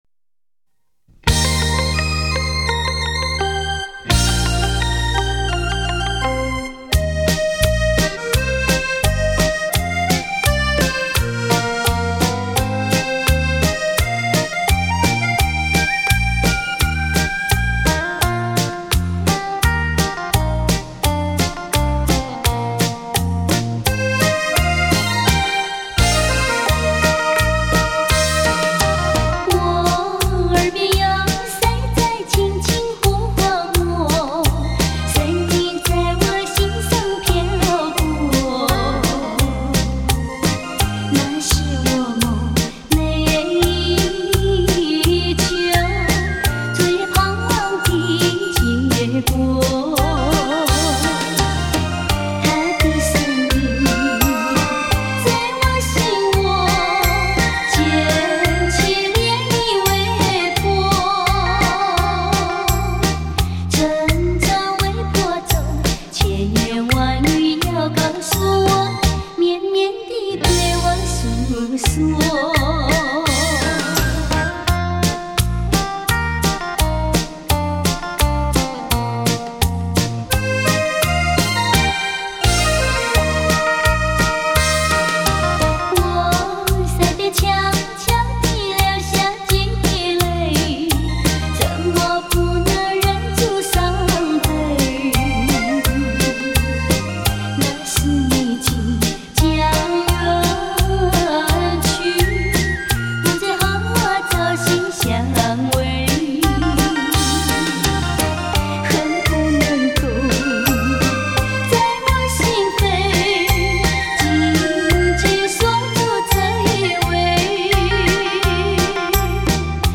山地发烧情歌